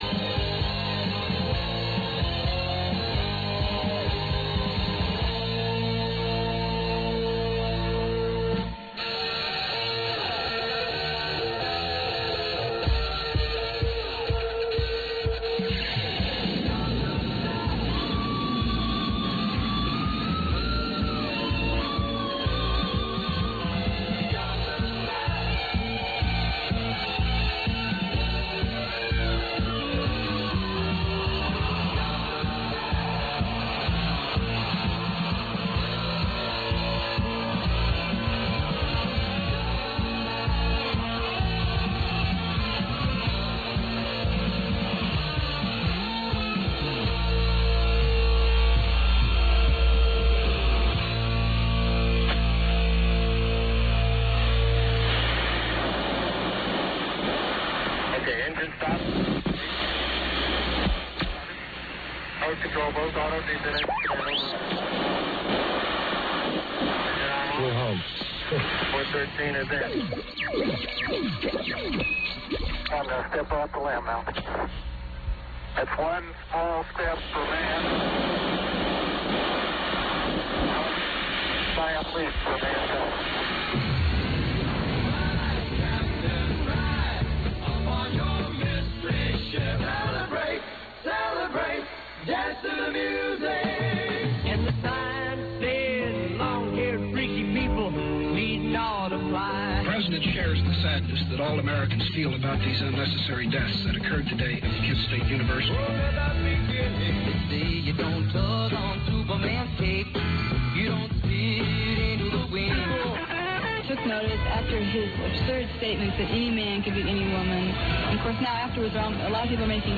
Received in Stoughton, MA by "yours' truly"